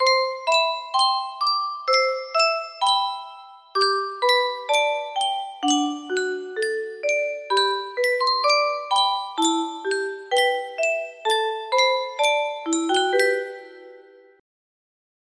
Clone of Yunsheng Music Box - In the Bleak Midwinter 6070 music box melody